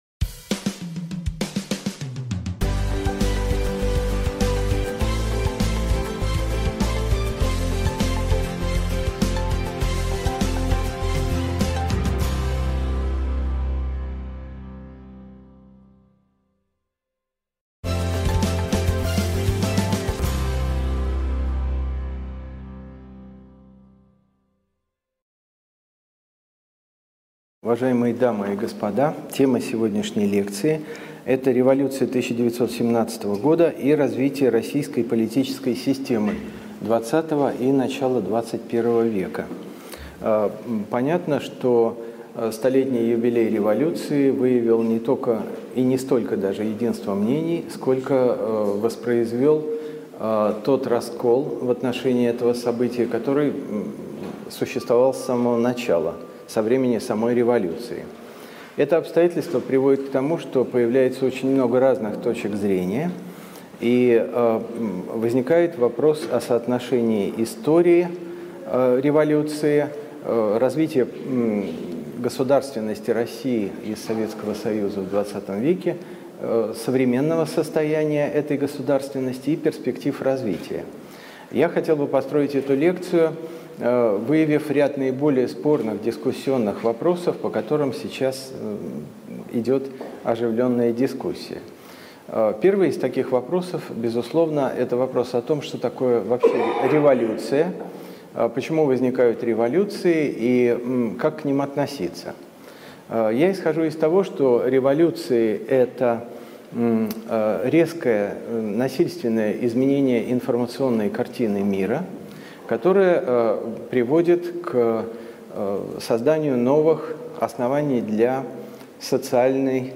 Aудиокнига Революция 1917 года и развитие российской политической системы Автор Андрей Медушевский.